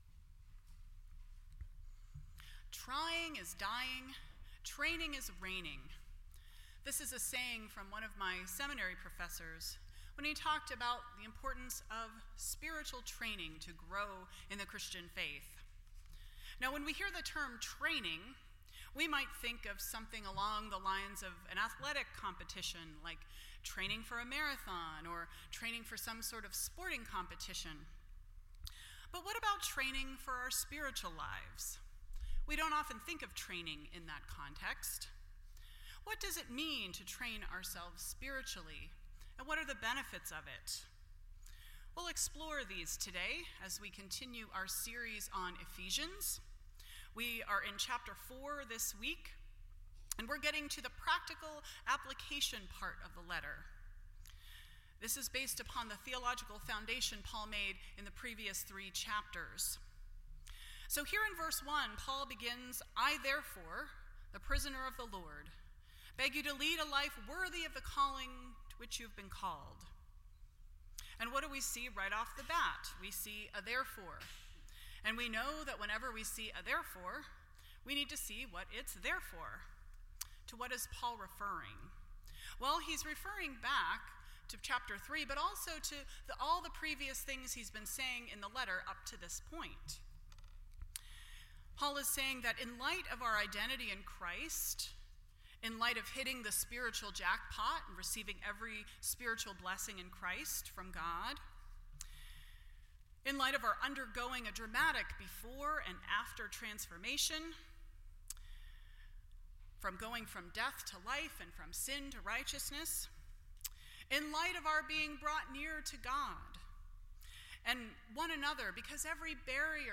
Ephesians Service Type: Sunday Morning %todo_render% Share This Story